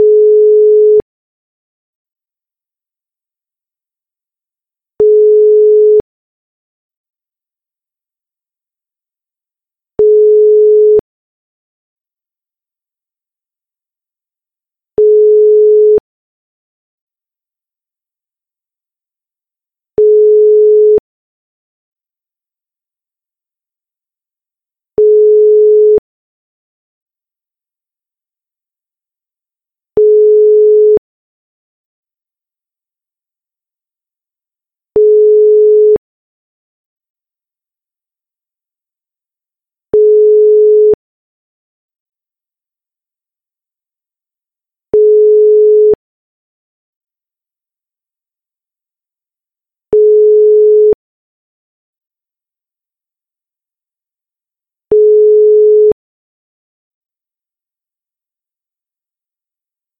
outgoing-rings.mp3